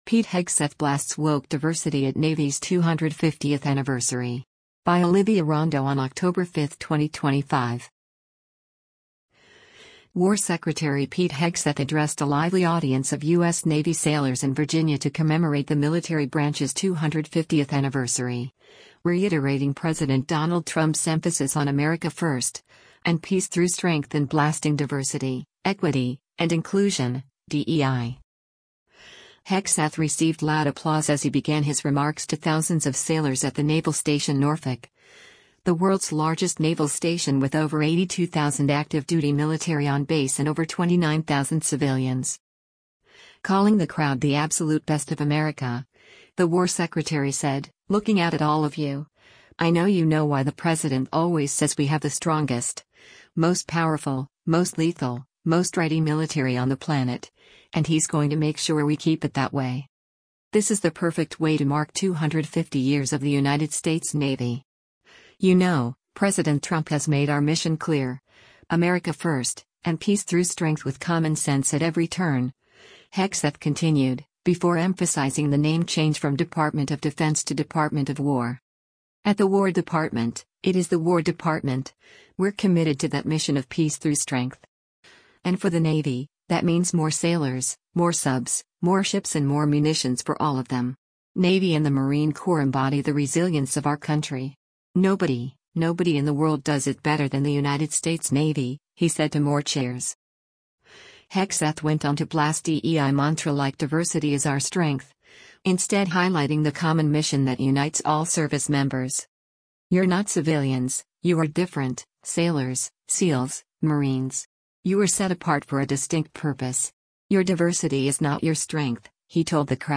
War Secretary Pete Hegseth addressed a lively audience of U.S. Navy Sailors in Virginia to commemorate the military branch’s 250th anniversary, reiterating President Donald Trump’s emphasis on “America first, and peace through strength” and blasting diversity, equity, and inclusion (DEI).
Hegseth received loud applause as he began his remarks to thousands of Sailors at the Naval Station Norfolk, the world’s largest naval station with over 82,000 active-duty military on base and over 29,000 civilians.